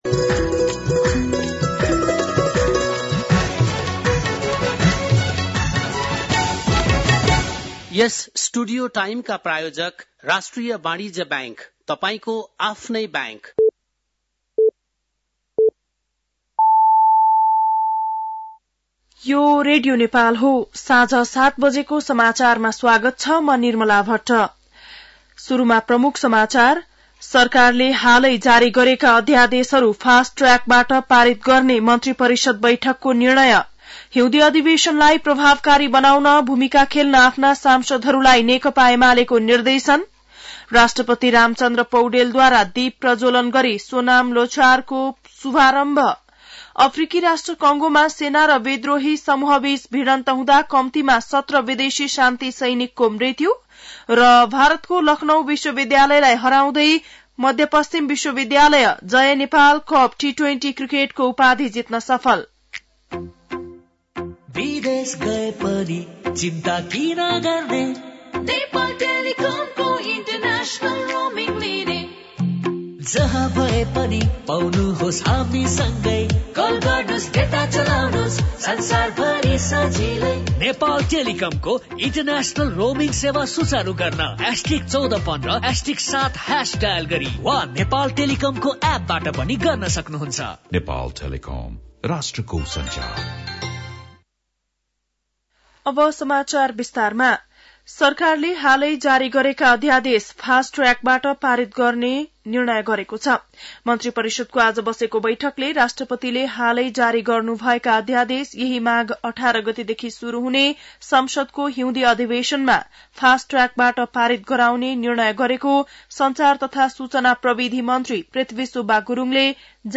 बेलुकी ७ बजेको नेपाली समाचार : १६ माघ , २०८१